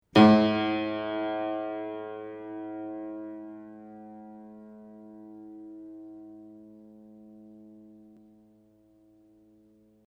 I campioni sonori che seguono sono stati registrati subito dopo il montaggio dei martelli (originali e replicati), sulle rispettive meccaniche (ottobre 2002): il suono dei martelli originali (nei quali le pelli sono solcate e un po' consunte alla sommità), è come facilmente prevedibile leggermente più pungente, di quello dei martelli replicati, al momento della registrazione intatti e del tutto privi di solchi.
Ascolta LA 1 (martello originale), quarto livello di sollecitazione meccanica